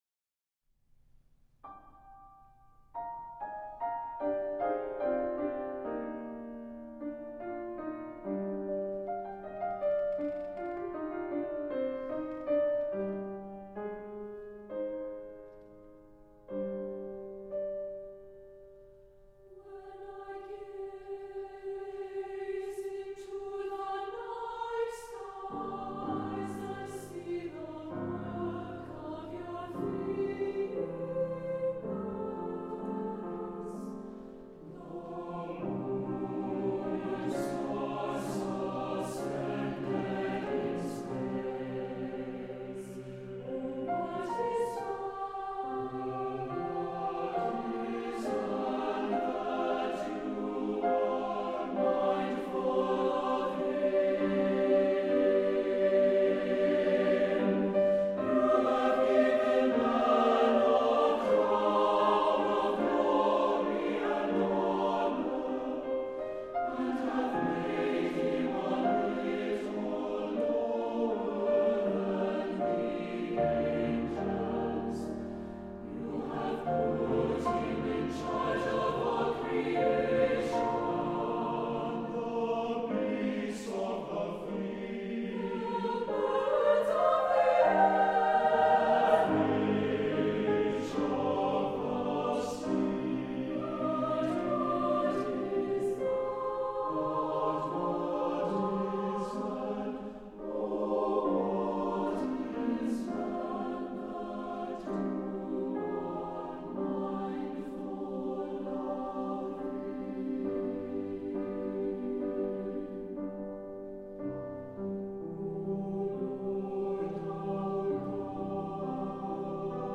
Summer Choir Anthems